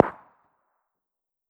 CookoffSounds / shotbullet / far_2.wav